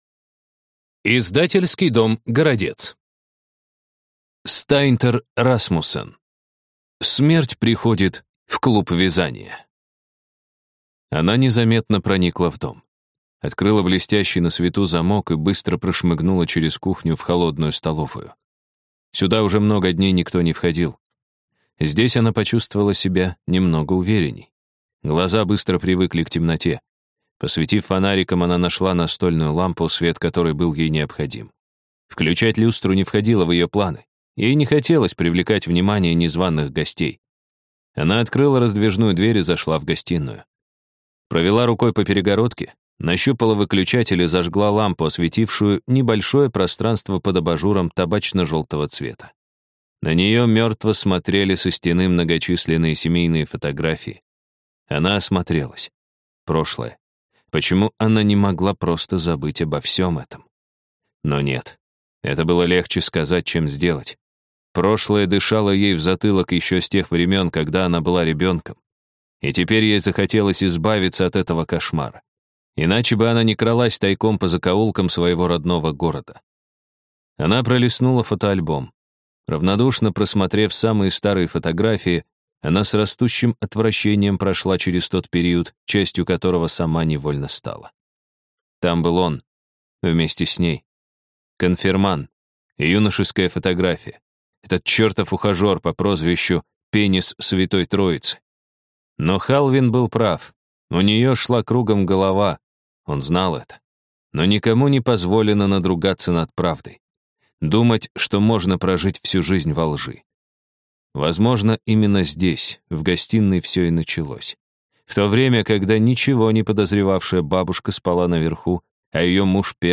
Аудиокнига Смерть приходит в клуб вязания | Библиотека аудиокниг